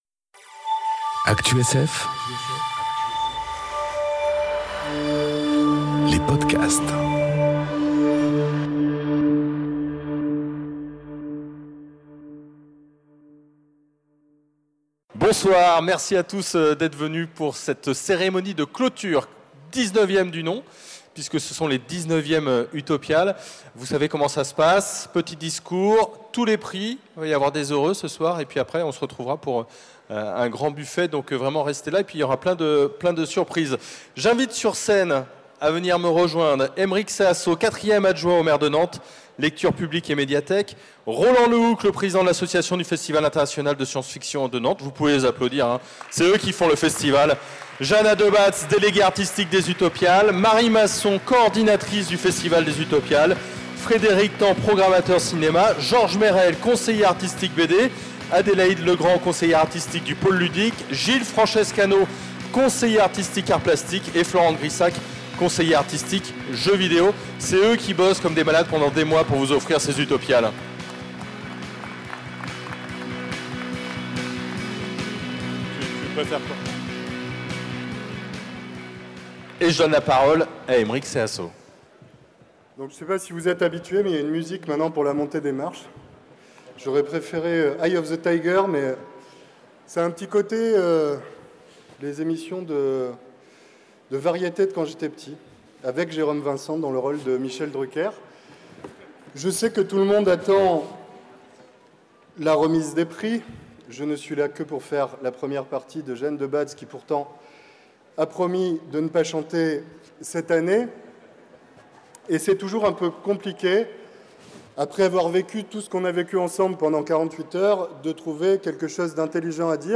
Soirée de remise des prix Utopiales 2018